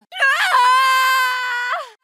Play MK9 Kitana Scream - SoundBoardGuy
PLAY MK9 Kitana K.O. Scream
mk9-kitana-scream.mp3